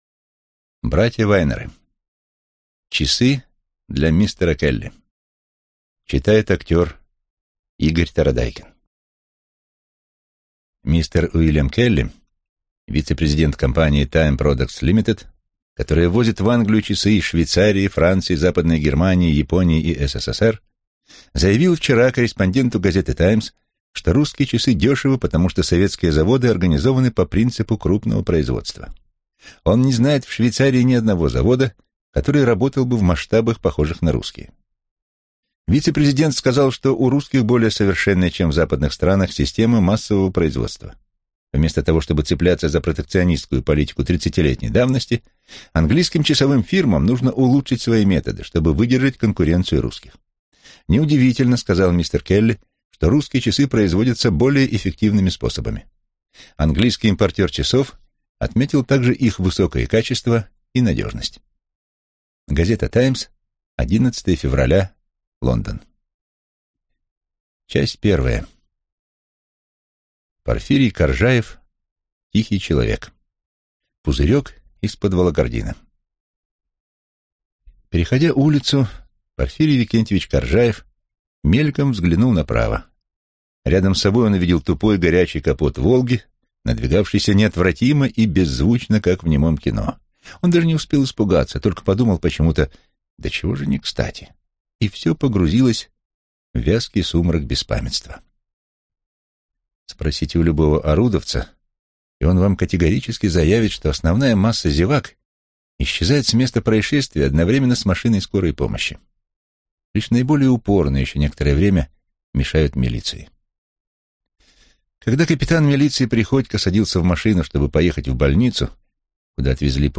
Аудиокнига Часы для мистера Келли | Библиотека аудиокниг